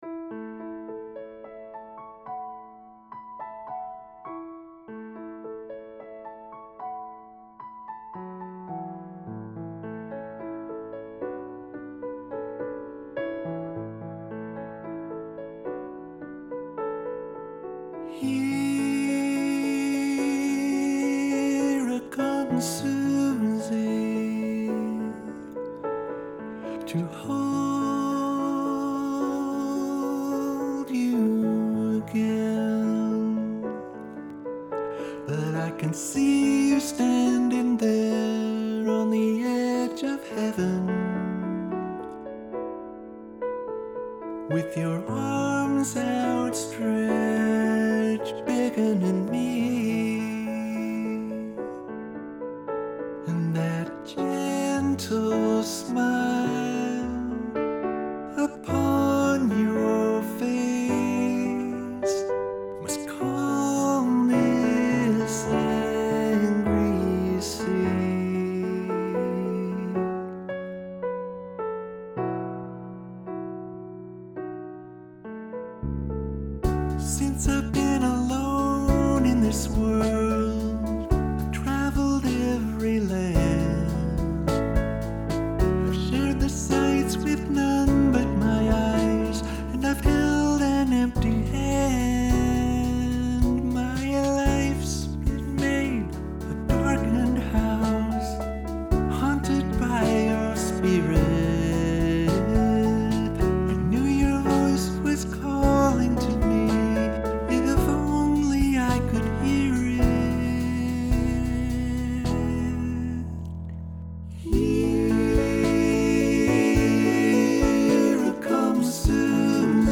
This is a cover of one of my all time favorite songs